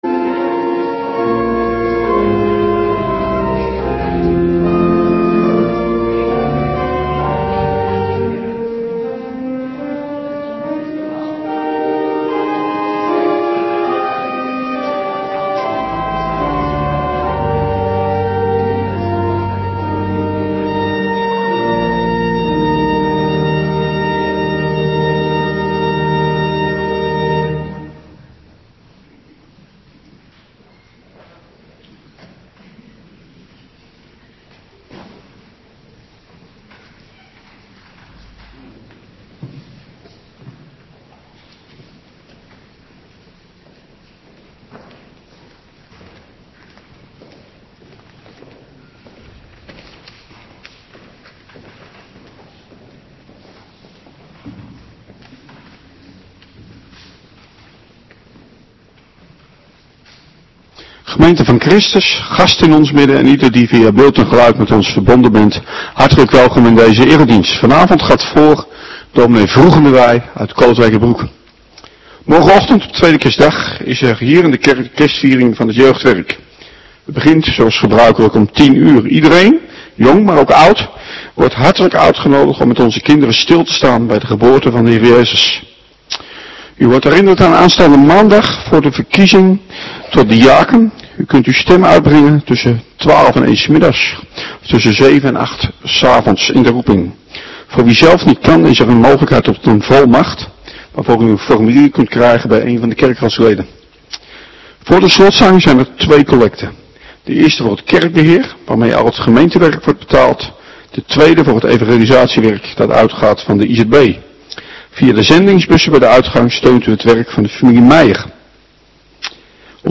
Avonddienst 25 december 2025